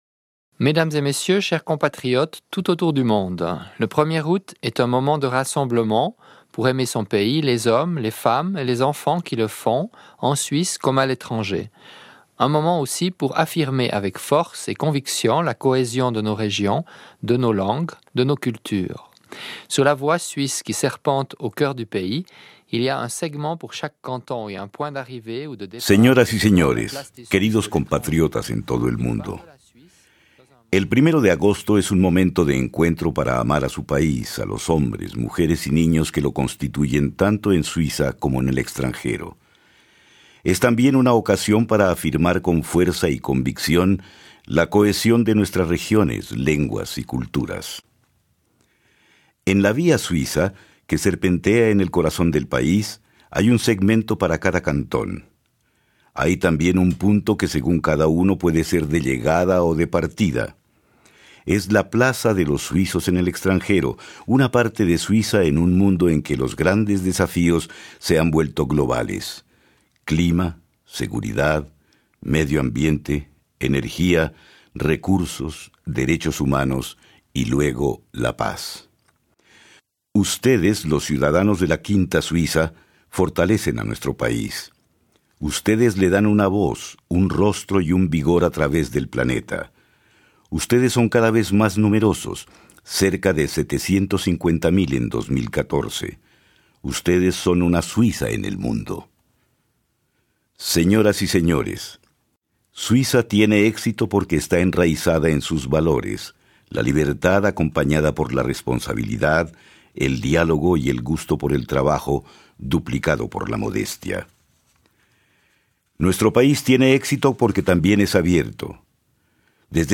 Discurso del presidente de la Confederación Didier Burkhalter a los suizos del exterior.